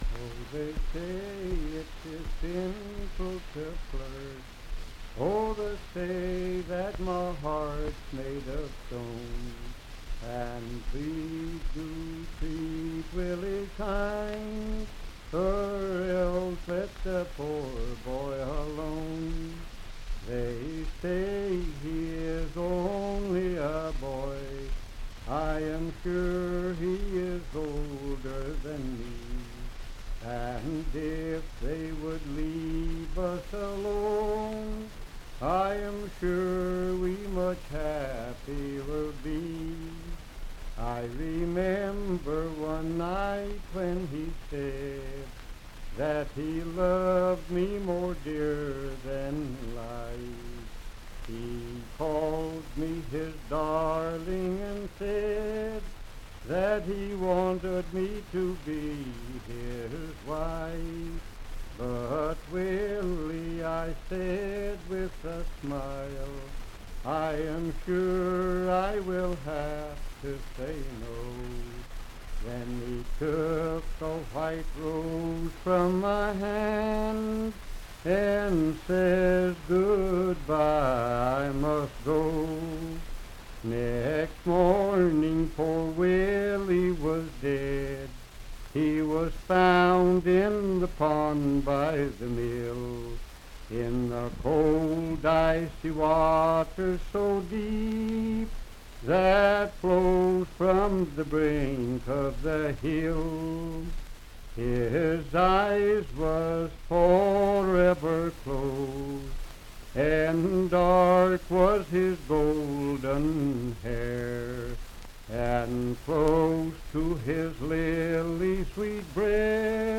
Unaccompanied vocal music
Voice (sung)
Franklin (Pendleton County, W. Va.), Pendleton County (W. Va.)